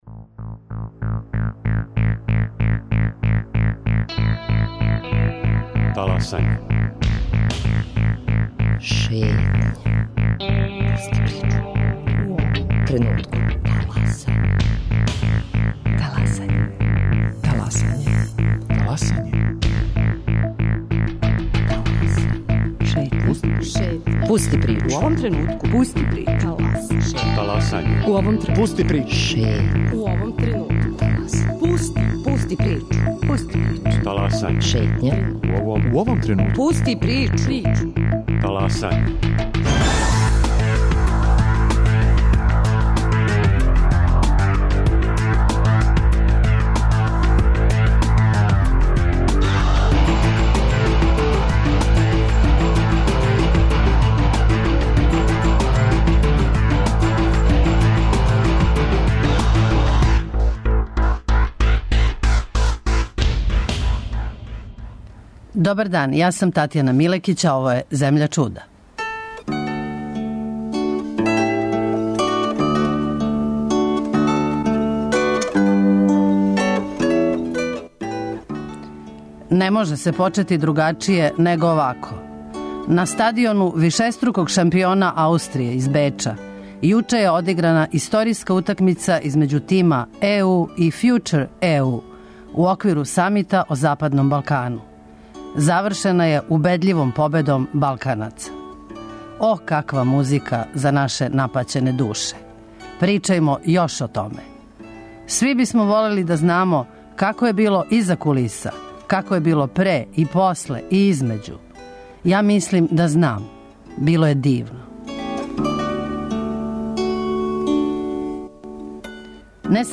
Изјаве пред вратима дома здравља: